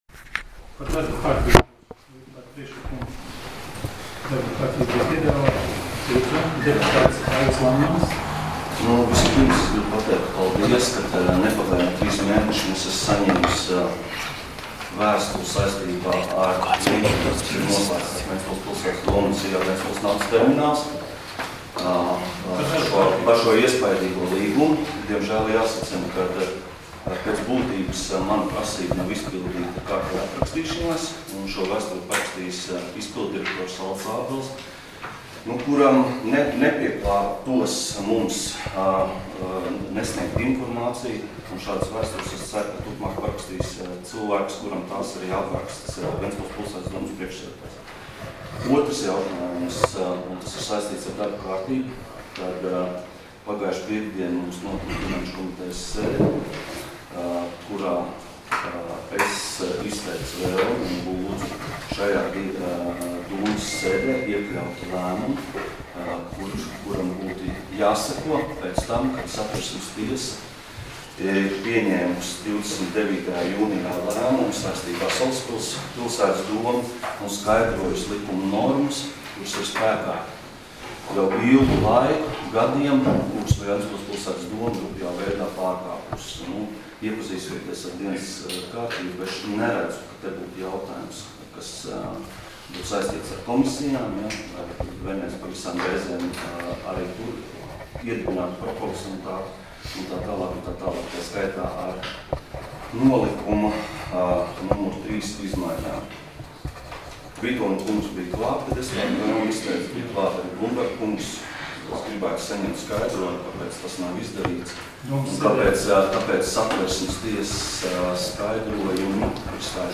Domes sēdes 13.07.2018. audioieraksts